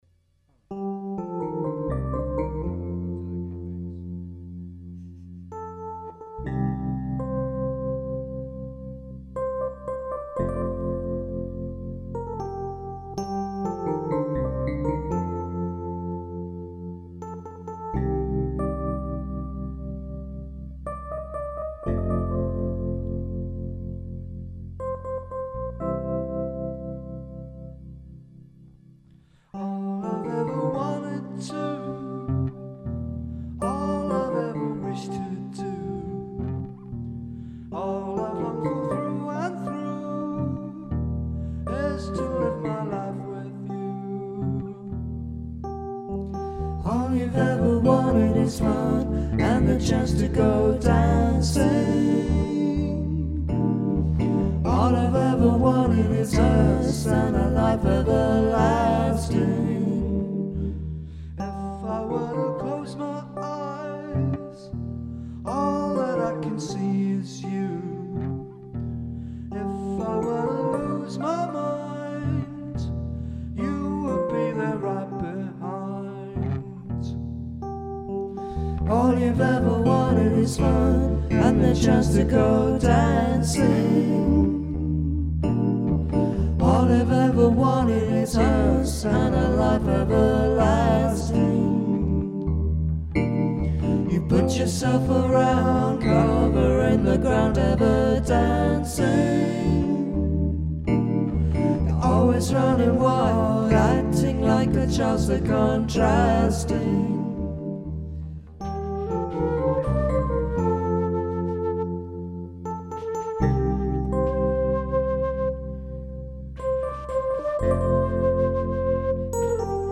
keyboards and lead vocals
bass
flute, harmony vocals & lyrics